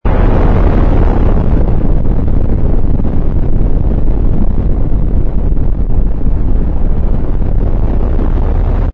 rumble_landing.wav